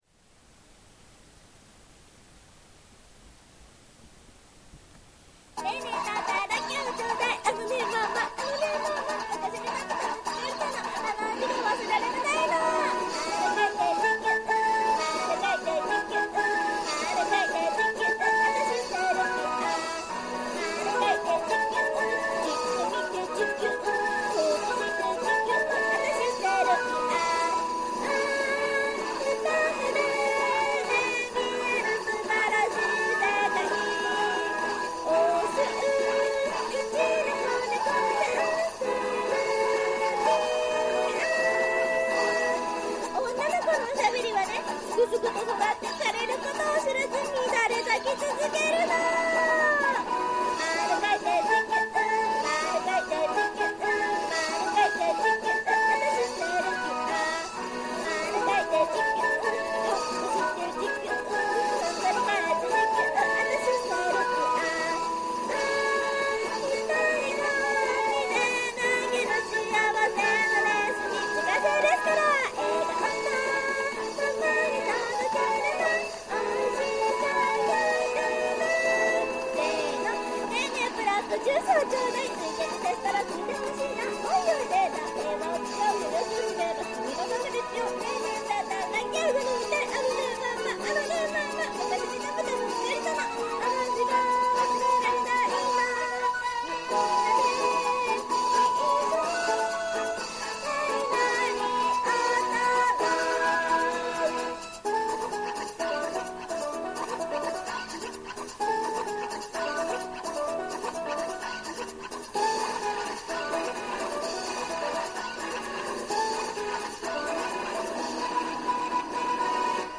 ちなみに、セルビアの方は上手くいったつもりです